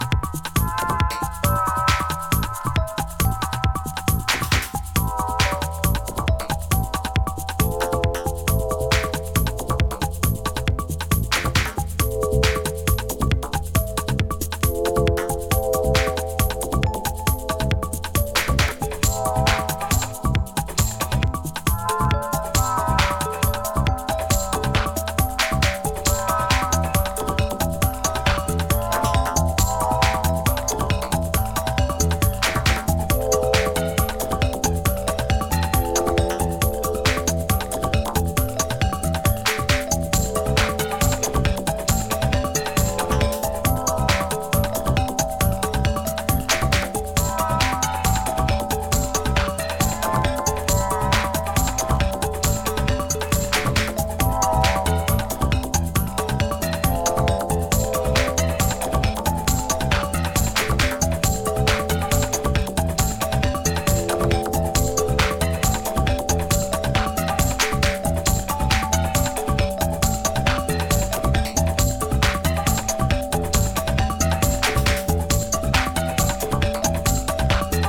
どのトラックも一筋縄ではいかない、オリジナルはサウンドが展開されてます。BPM130オーバーの疾走感溢れる